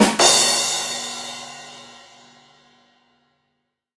drumrollEnd.ogg